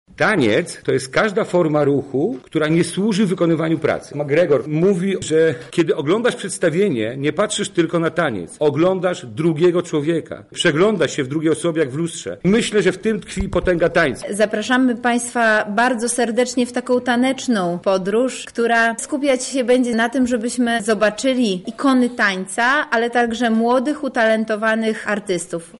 Zastępca prezydenta Beata Stepaniuk-Kuśmierzak